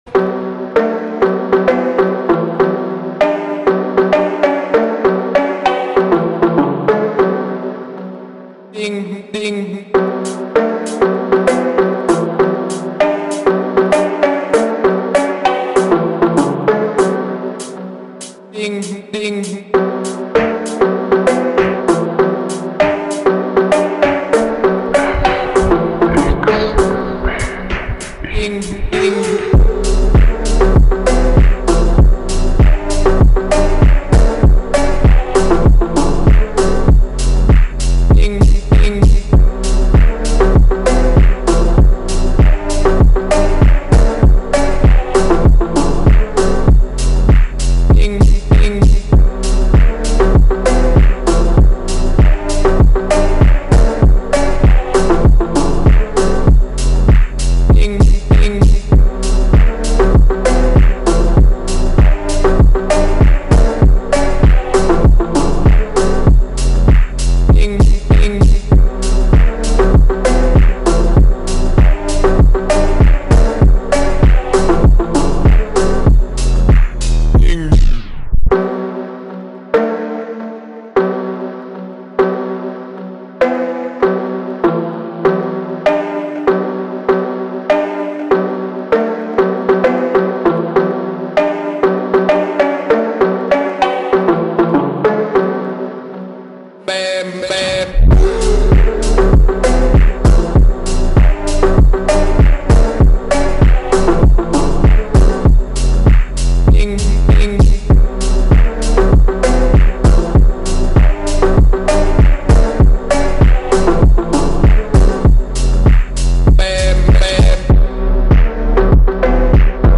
ریمیکس کاهش سرعت آهسته